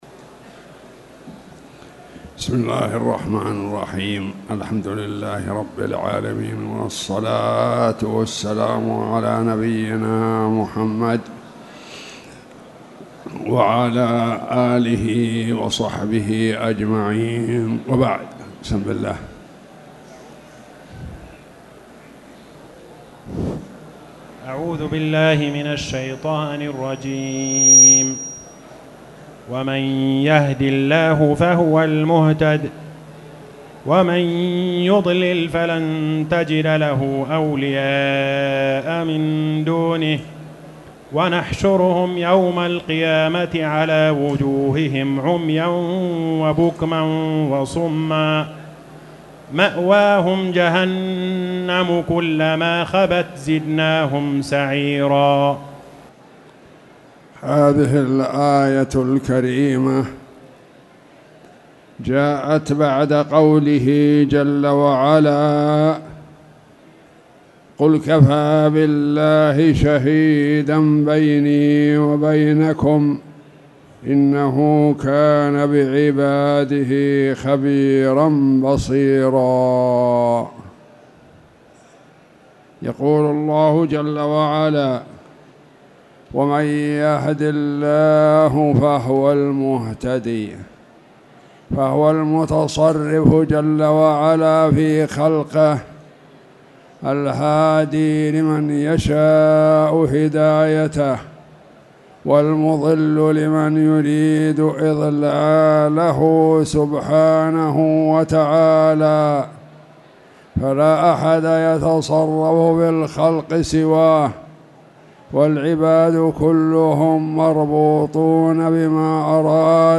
تاريخ النشر ١ ربيع الأول ١٤٣٨ هـ المكان: المسجد الحرام الشيخ